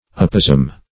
Search Result for " apozem" : The Collaborative International Dictionary of English v.0.48: Apozem \Ap"o*zem\ ([a^]p"[-o]*z,ecr/m), n. [L. apozema, Gr.
apozem.mp3